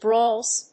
/brɔlz(米国英語), brɔ:lz(英国英語)/